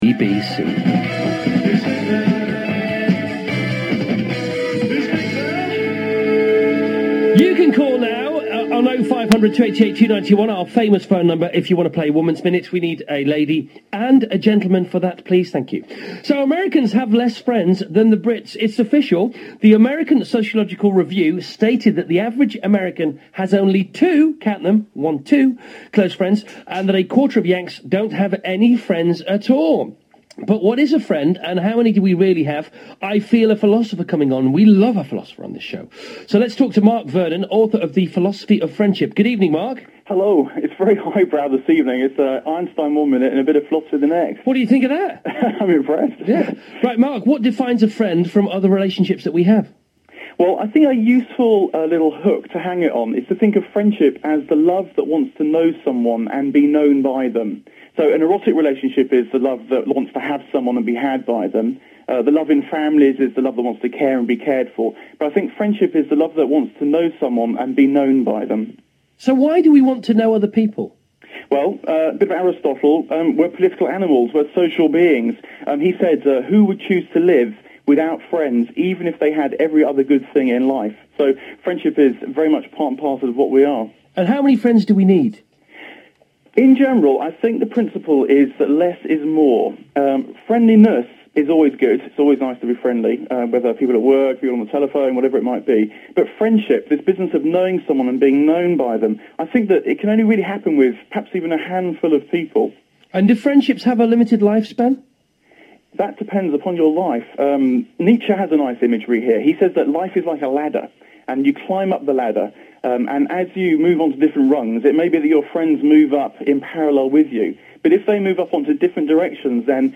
Chris Evans BBC Radio2 show, interviewing philosopher